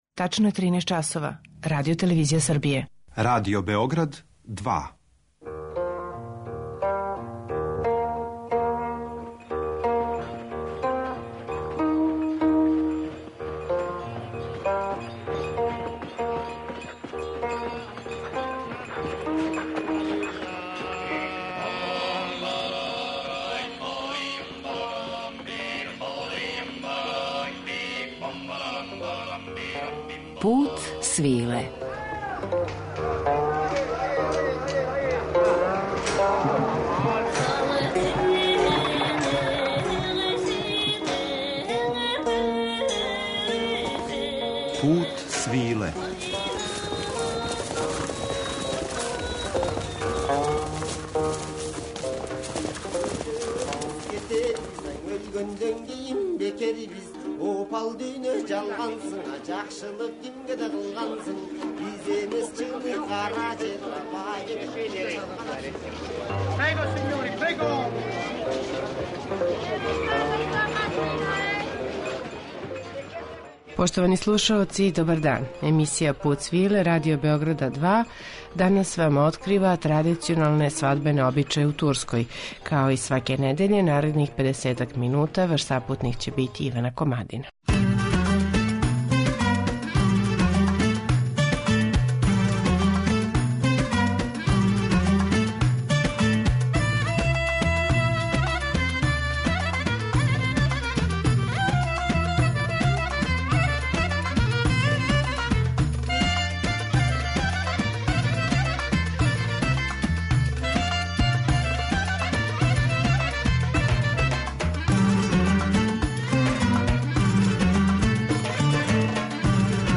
Обреди који прате традиционално турско венчање, уз ансамбл кларинетисте Селима Сеслера
Музички рам за слику светковине обезбедиће ансамбл кларинетисте Селима Сеслера, који је обрадио и снимио мелодије које се изводе на свадбама у Турској.